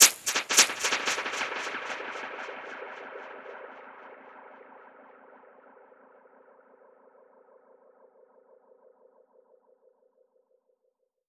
Index of /musicradar/dub-percussion-samples/85bpm
DPFX_PercHit_C_85-05.wav